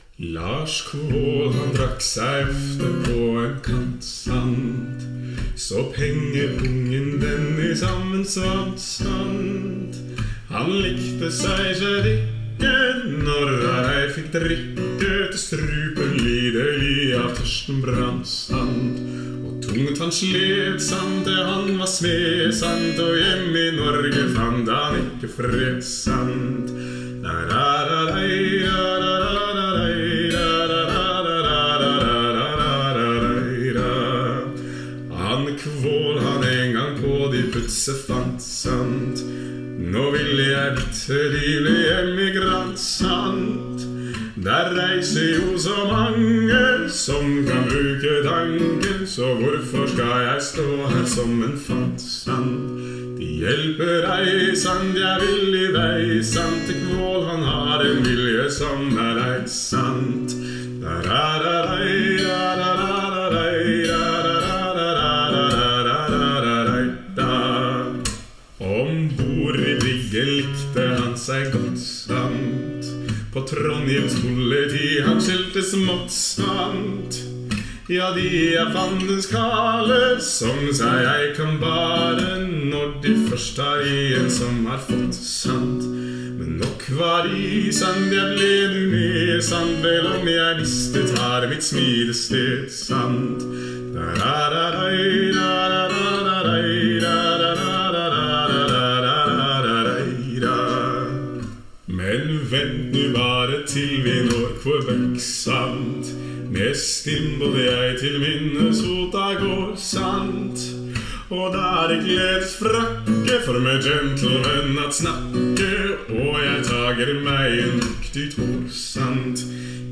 for arbeid med innstudering, fremføring, og innspilling, inkludert miksing og redigering